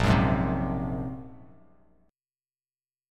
A#7sus2#5 chord